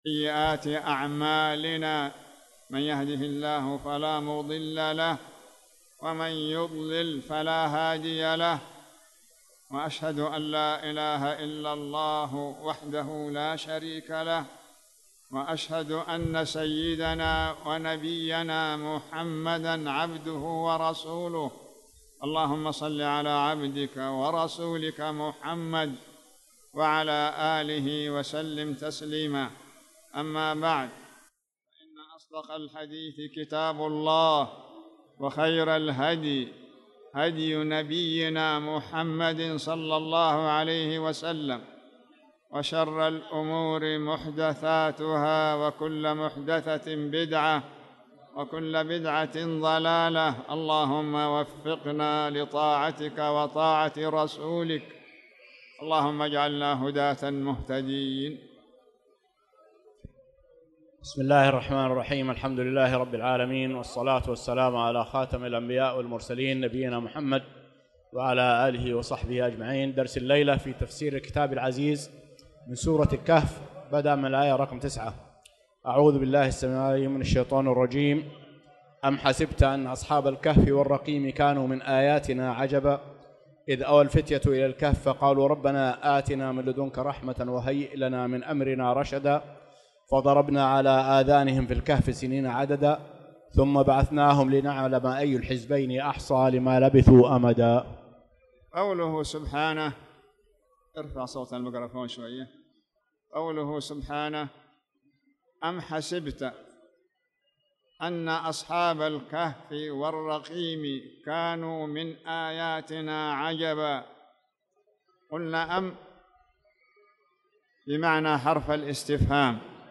تاريخ النشر ٢٠ شوال ١٤٣٧ هـ المكان: المسجد الحرام الشيخ